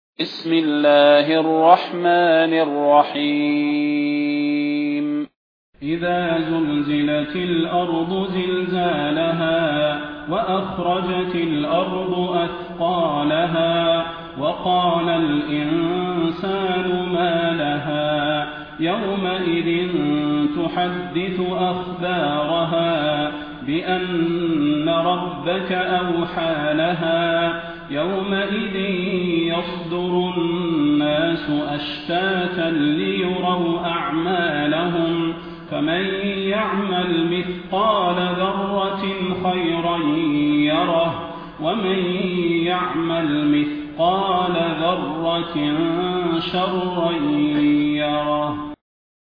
المكان: المسجد النبوي الشيخ: فضيلة الشيخ د. صلاح بن محمد البدير فضيلة الشيخ د. صلاح بن محمد البدير الزلزلة